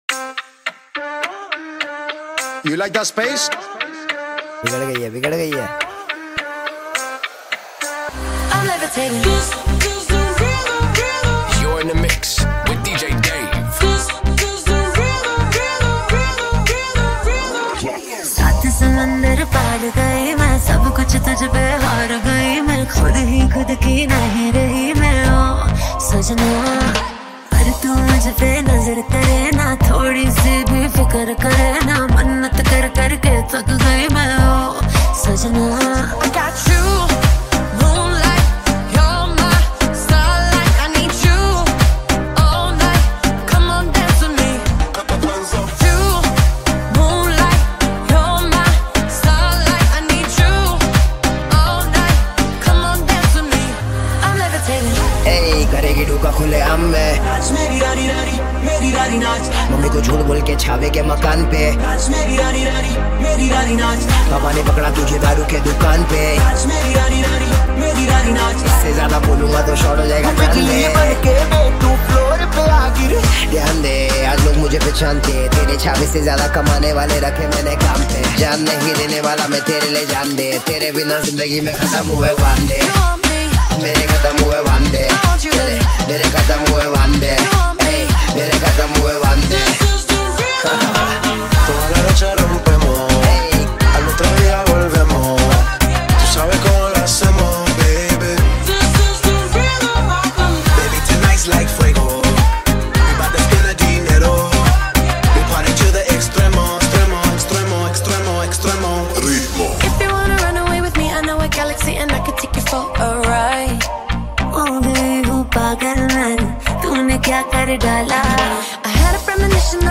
High quality Sri Lankan remix MP3 (23.6).
remix